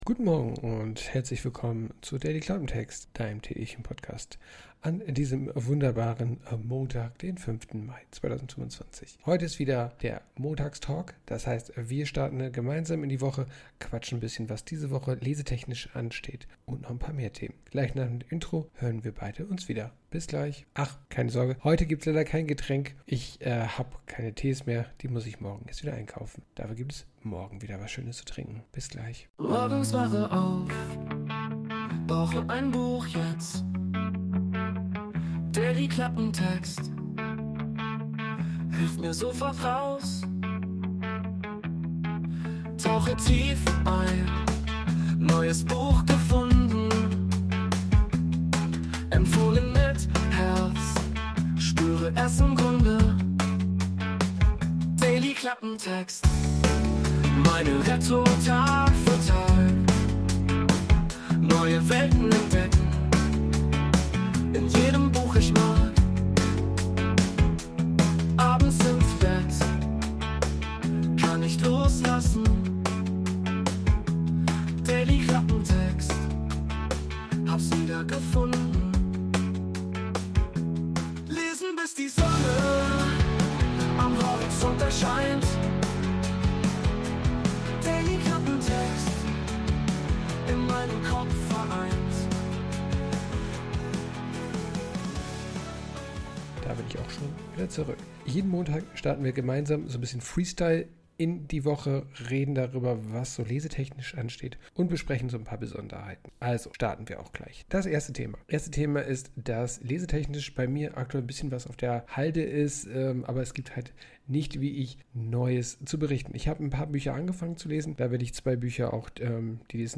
### Quelle: Intromusik: Wurde mit der KI Sonos erstellt.